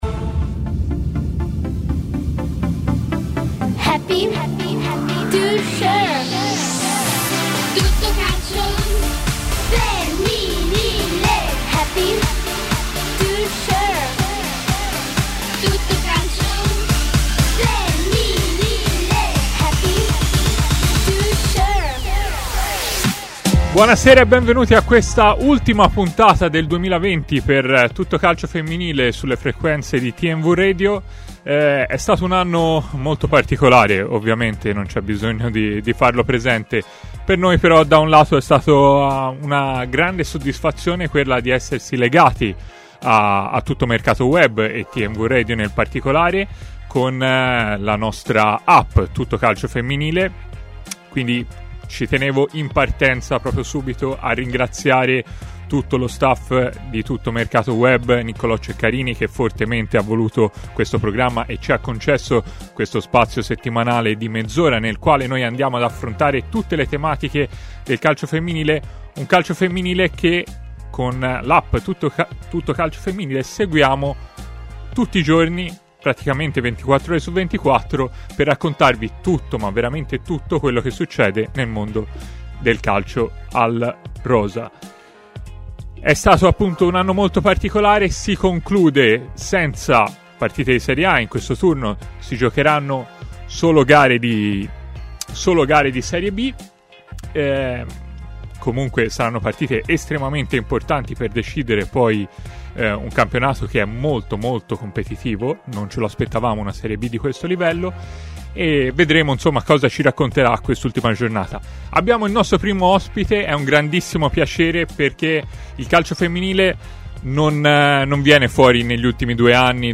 è intervenuto in diretta a TMW Radio, nel corso di Tutto Calcio Femminile